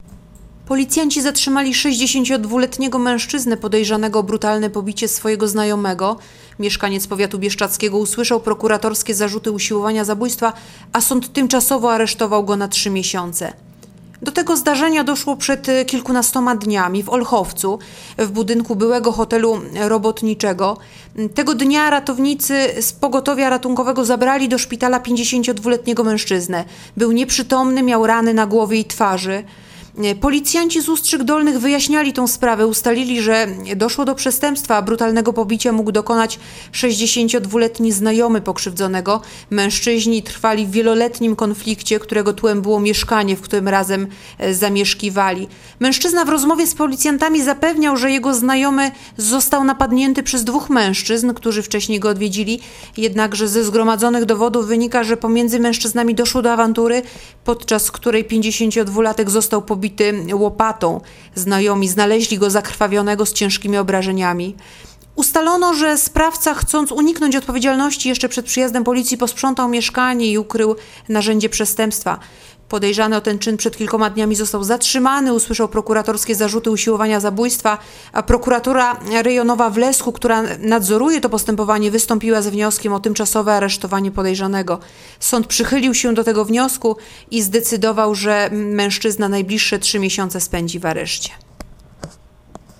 Mówi podkom.